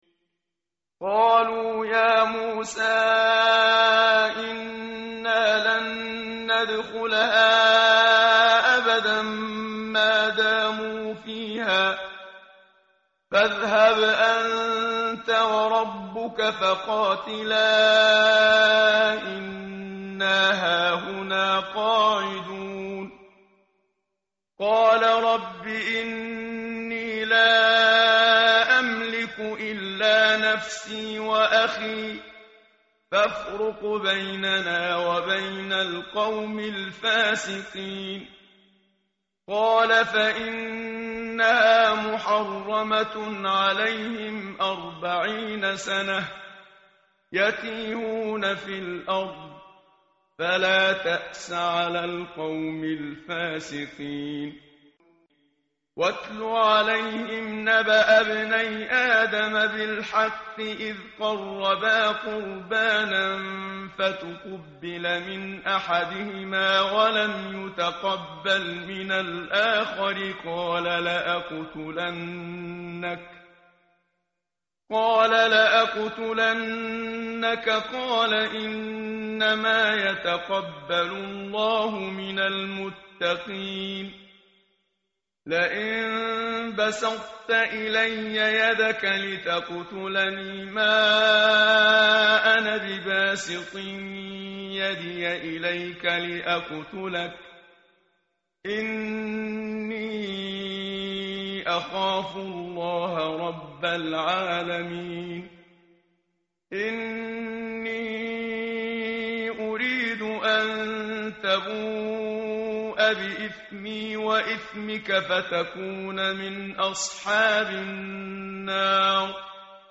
متن قرآن همراه باتلاوت قرآن و ترجمه
tartil_menshavi_page_112.mp3